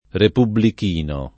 repubblichino
vai all'elenco alfabetico delle voci ingrandisci il carattere 100% rimpicciolisci il carattere stampa invia tramite posta elettronica codividi su Facebook repubblichino [ repubblik & no ] agg. e s. m. (stor.)